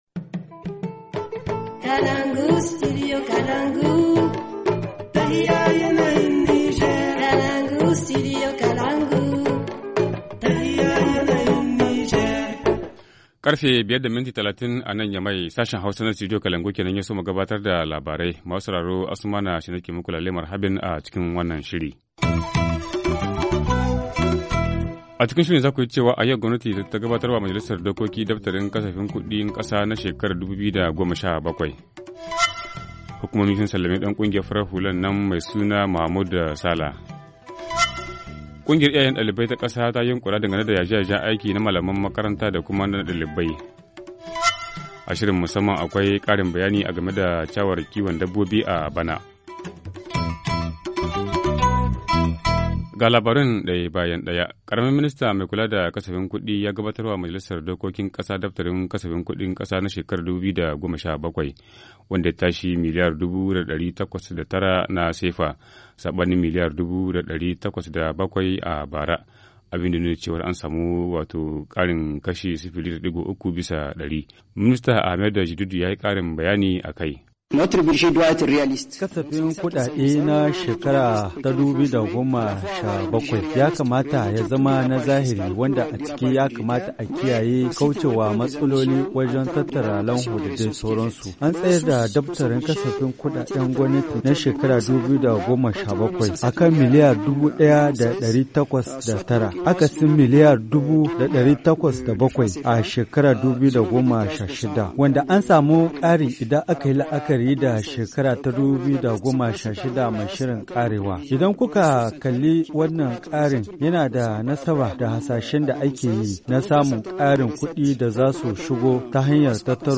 Vous l’entendrez ainsi qu’un représentant de l’opposition.